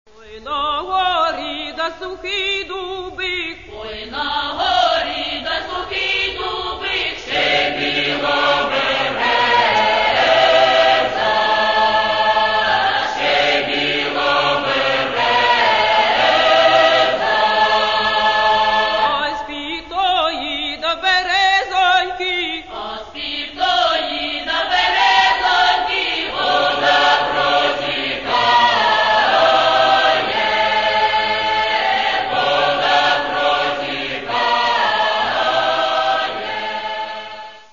Catalogue -> Folk -> Traditional Solo Singing and Choirs